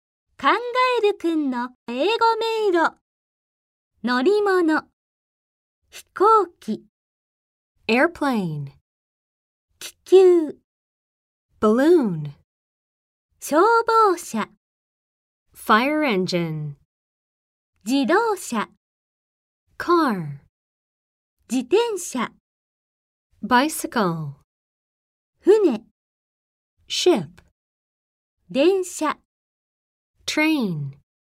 ネイティブスピーカーによる発音でお聞きいただけます。